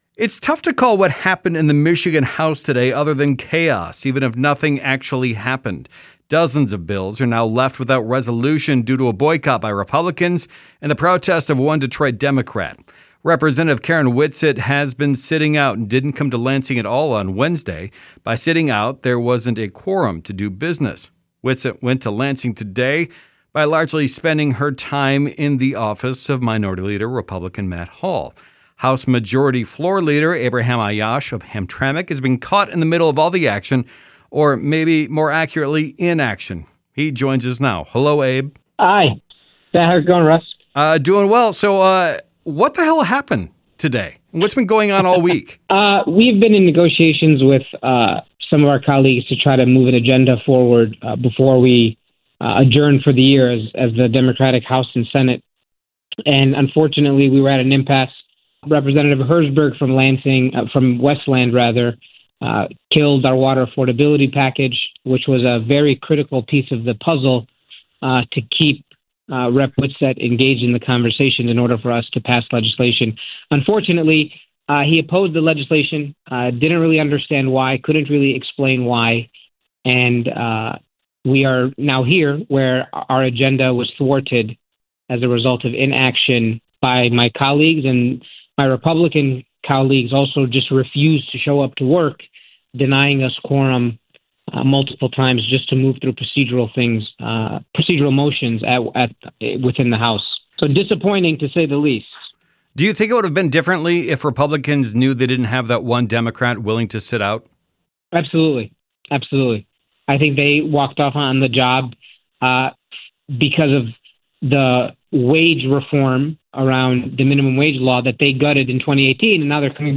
Listen: Majority Floor Leader Abraham Aiyash speaks on boycott in Michigan House